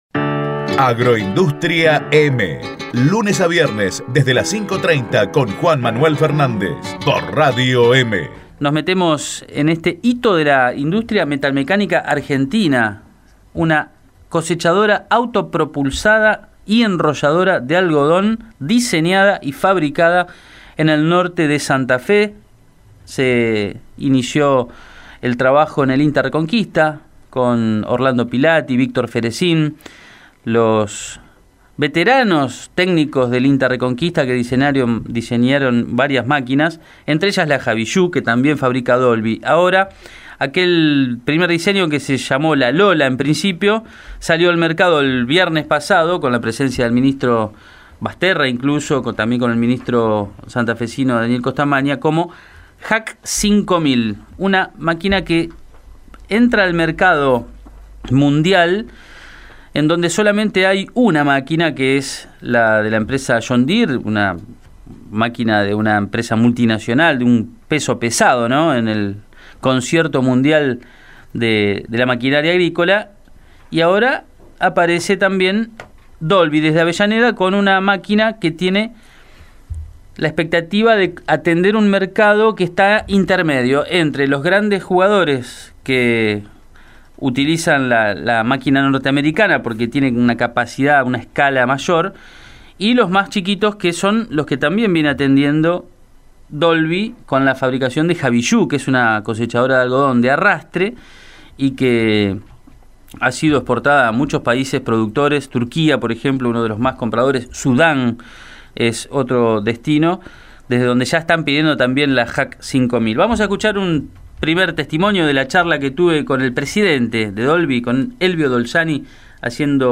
explicó por radio EME que se proponen un plan de fabricación paulatino y moderado.